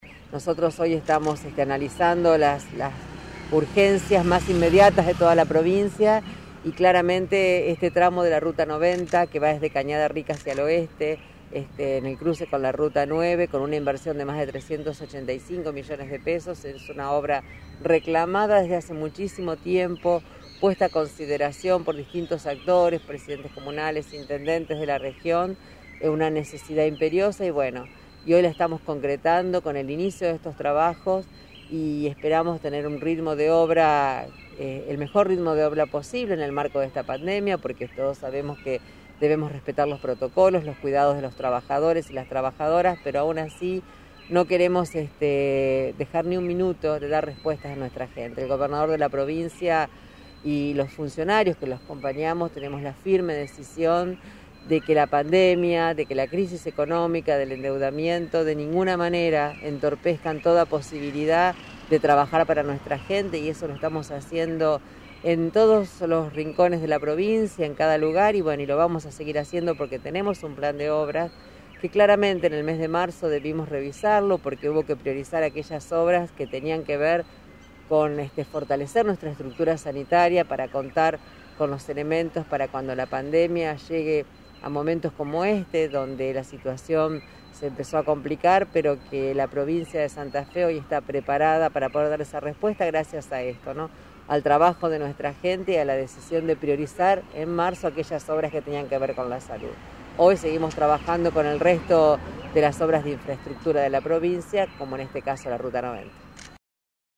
Silvina Frana, Ministra de Infraestructura, Servicios Públicos y Hábitat